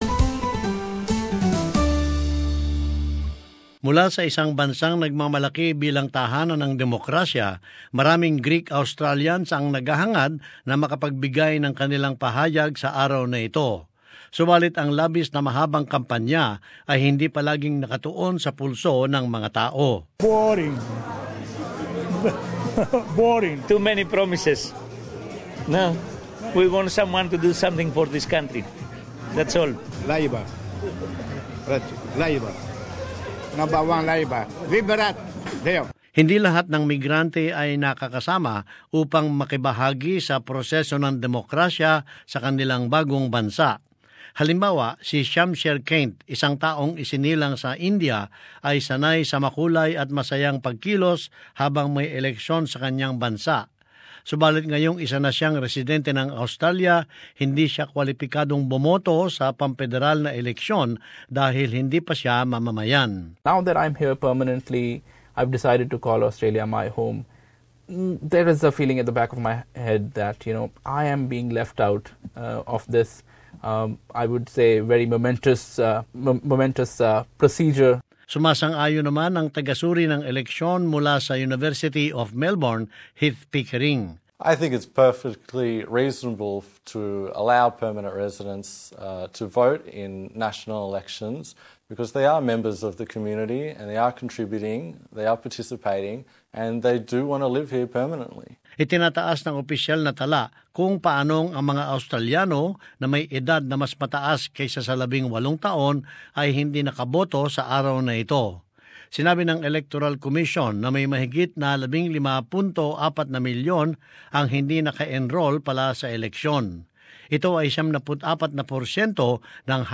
As this report shows, it has prompted calls for an electoral-law rethink to extend the right-to-vote beyond just citizens.